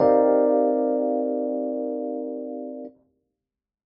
• That’s it! There you have the minor 11 chord, which might have just that neo soul sound you are looking for.
C minor 11 neo soul chord
c-minor-11-neo-soul-chord.mp3